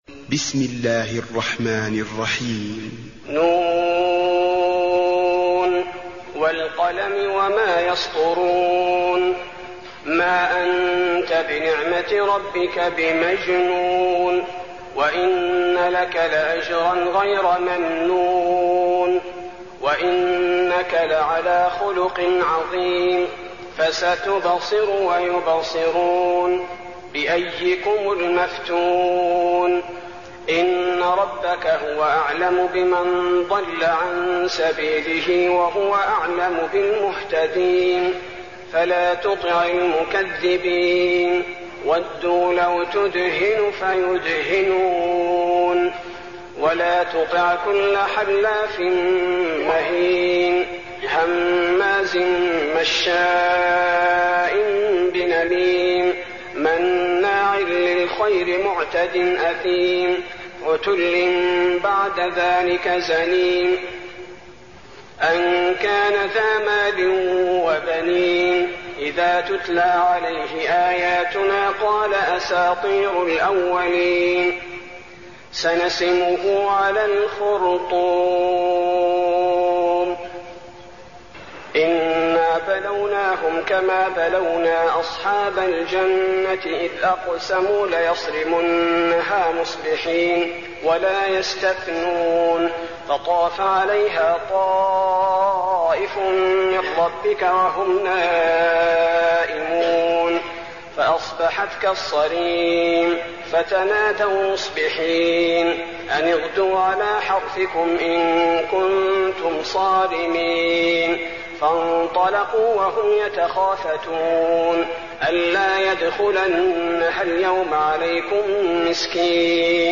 المكان: المسجد النبوي القلم